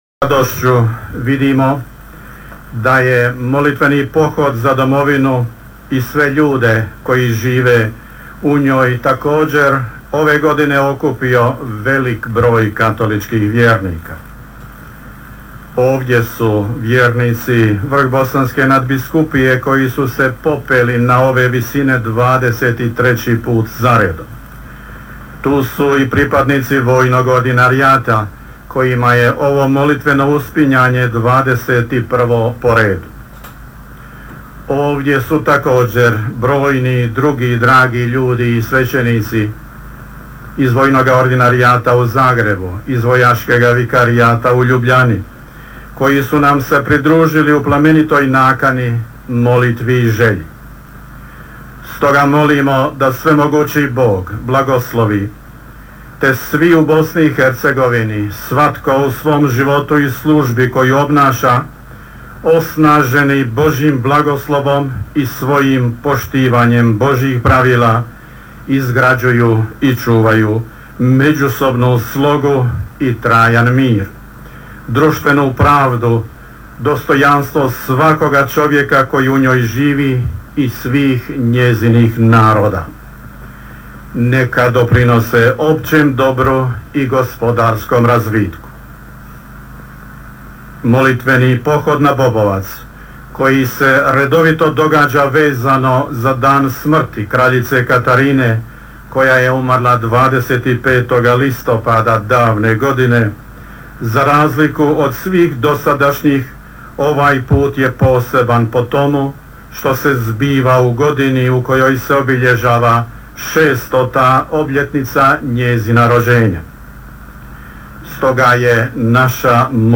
Audio: Propovijed nadbiskupa Vukšića na misnom slavlju na Bobovcu na Molitveni dan za domovinu Bosnu i Hercegovinu